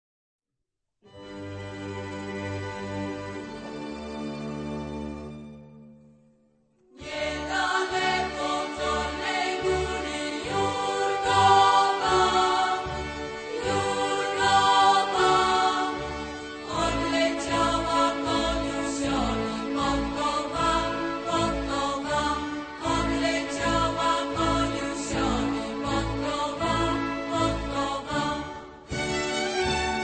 Archival recordings from years 1961-1970